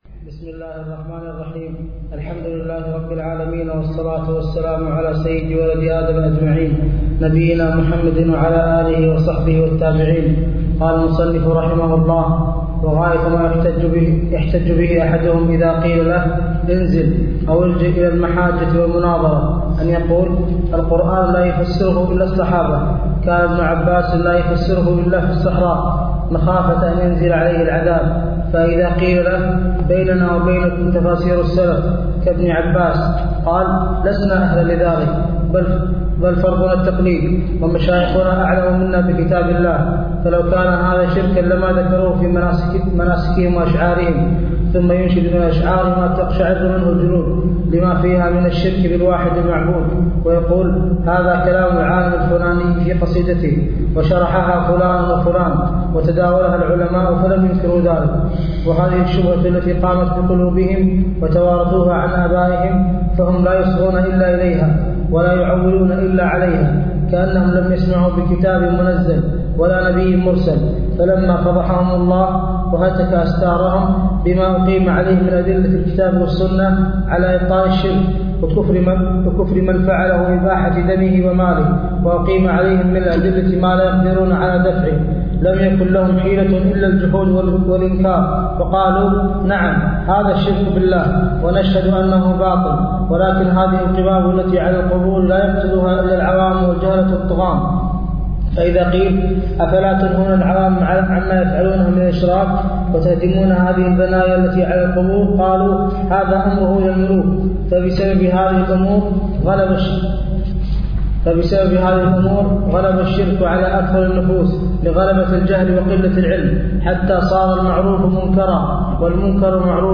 عنوان المادة الدرس (6)شرح رسالة الرد على القبوريين لحمد بن معمر تاريخ التحميل السبت 31 ديسمبر 2022 مـ حجم المادة 48.80 ميجا بايت عدد الزيارات 242 زيارة عدد مرات الحفظ 110 مرة إستماع المادة حفظ المادة اضف تعليقك أرسل لصديق